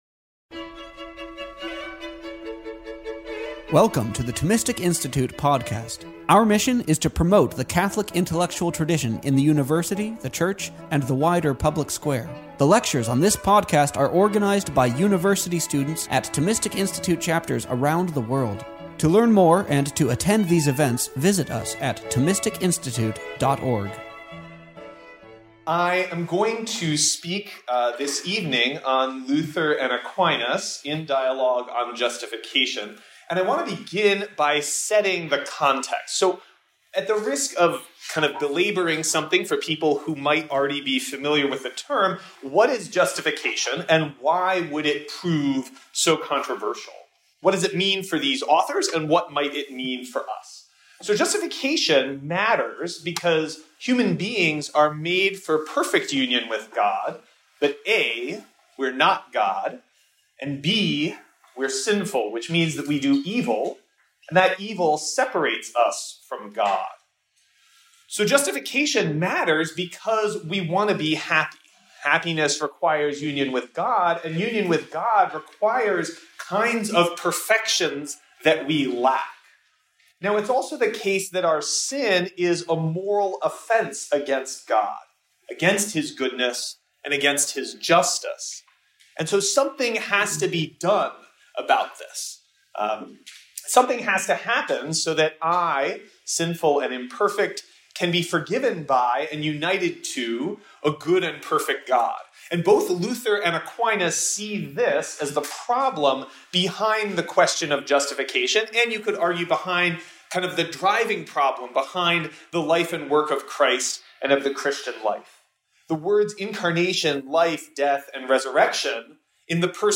This lecture was given on April 27th, 2023 at Cornell University.